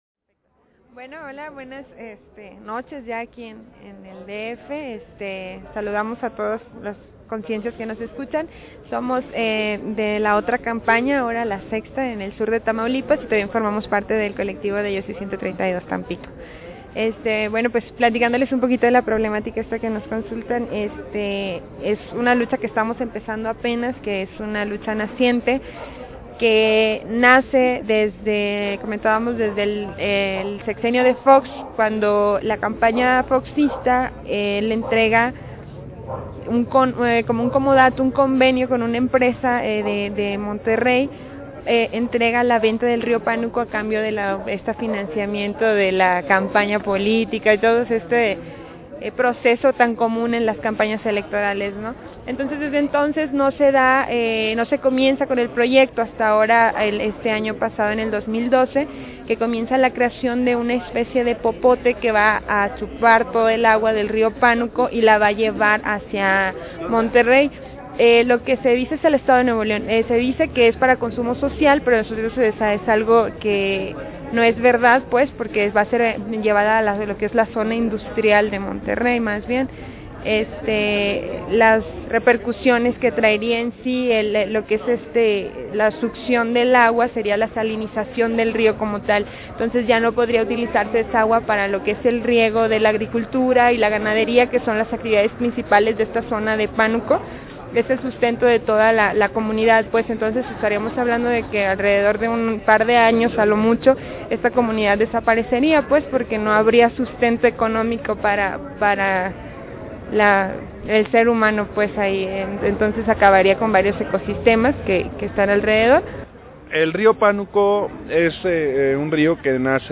Entrevista con compañerxs
Entrevista_del_Rio_Panuco.mp3